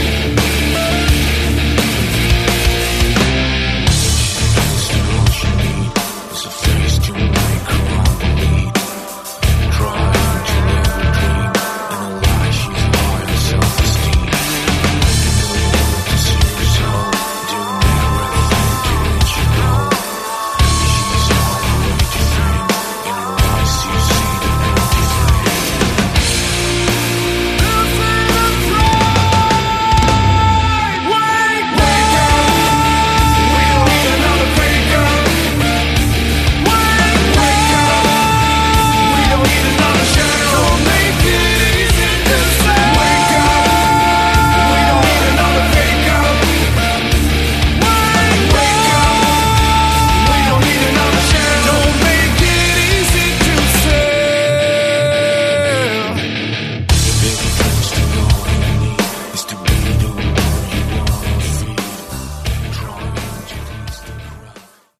Category: Melodic Hard Rock
Vocals
Guitars
Drums
Bass
Keyboards